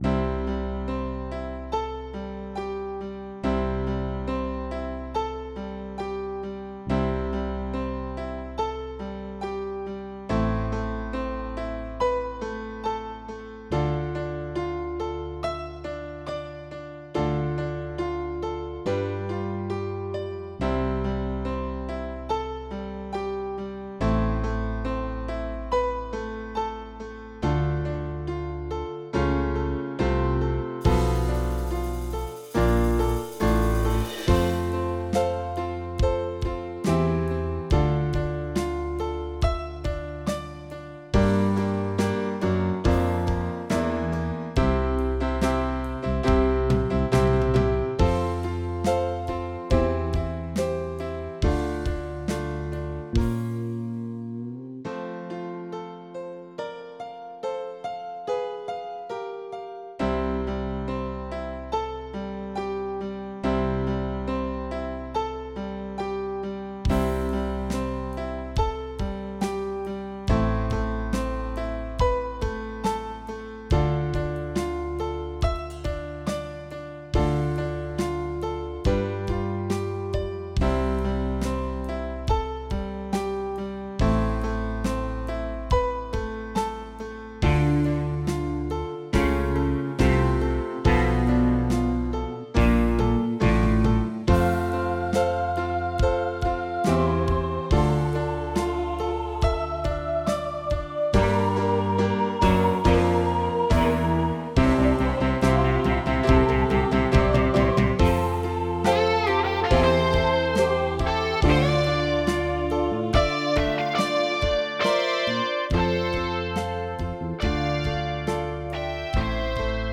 base senza melodia